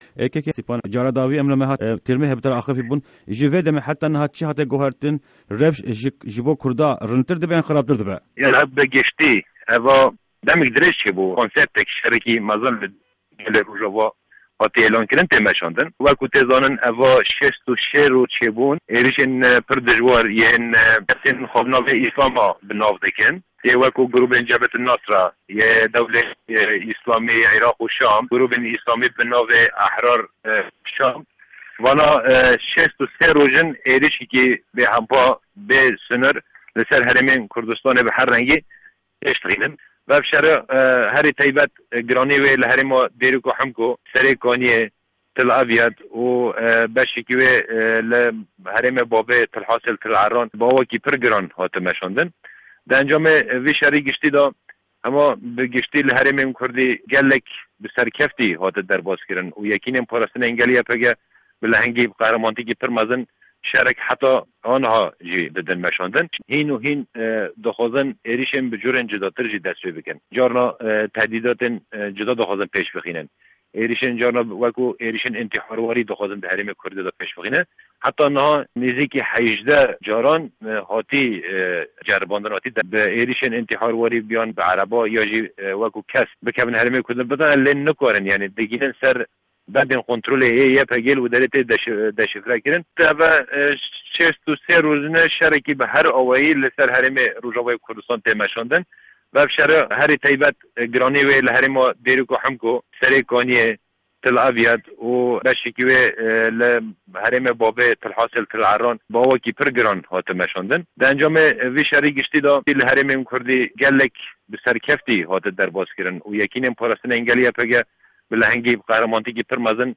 Di hevpeyvîna taybet ya Dengê Amerîka de Sîpan Hemo, Fermandarê Giştî yê Yekîneyên Parastine Gel (YPG) agahîyên girîng li ser şerên berdewam dide û pêşketinên dawî dinirxîne.